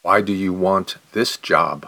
06_question_slow.mp3